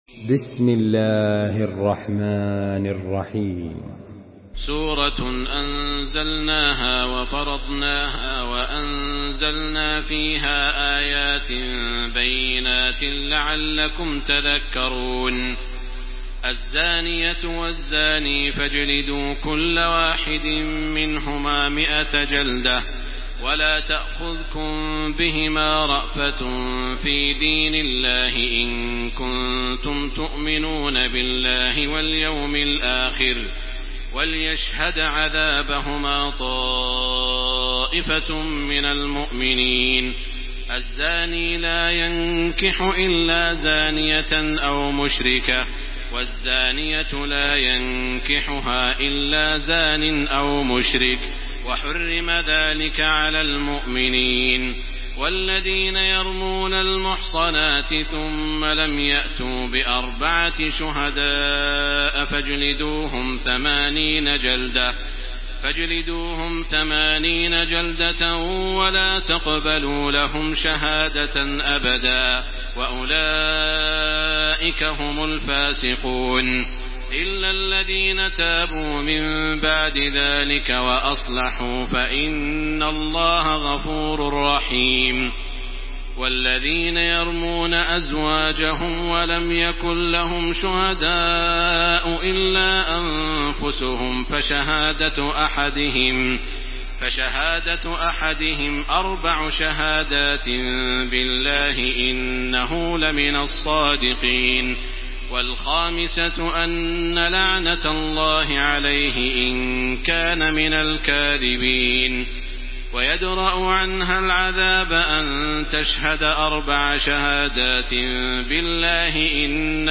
Surah An-Nur MP3 by Makkah Taraweeh 1434 in Hafs An Asim narration.
Murattal